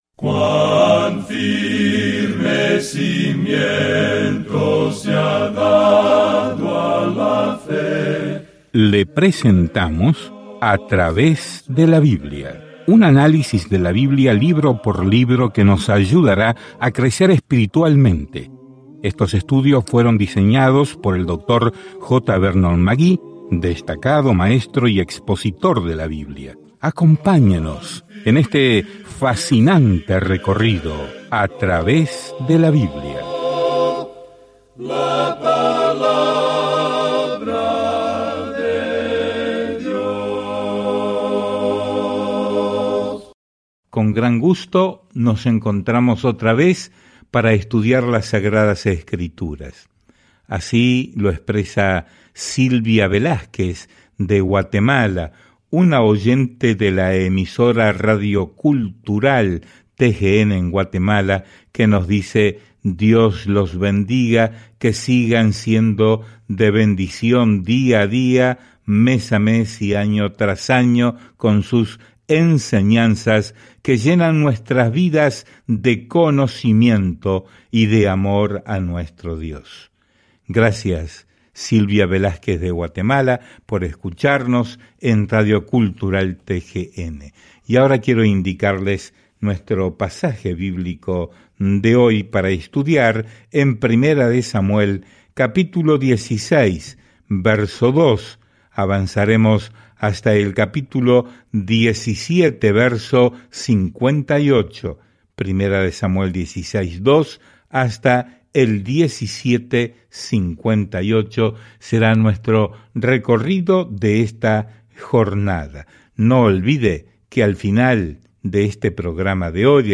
Escritura 1 SAMUEL 16:2-23 1 SAMUEL 17 Día 9 Iniciar plan Día 11 Acerca de este Plan Primero Samuel comienza con Dios como rey de Israel y continúa la historia de cómo Saúl, y luego David, se convirtió en rey. Viaje diariamente a través de Primera de Samuel mientras escucha el estudio de audio y lee versículos seleccionados de la palabra de Dios.